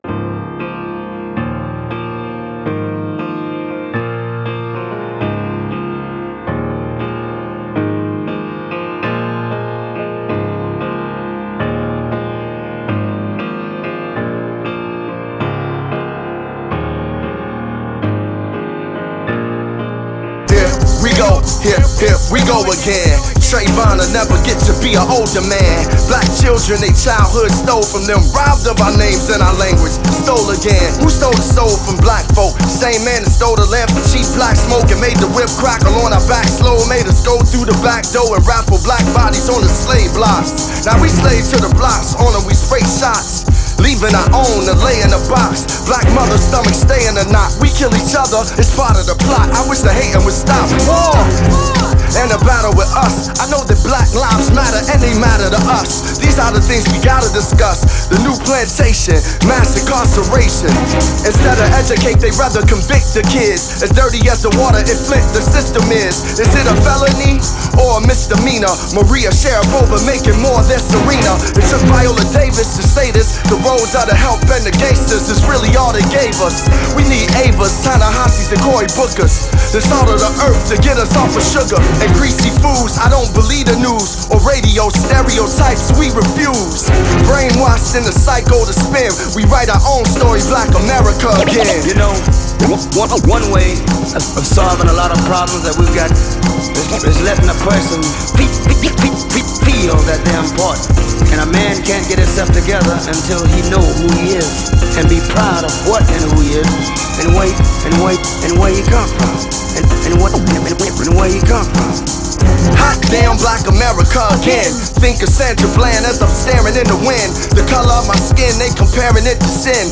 As the track fades out,  the music begins to smooth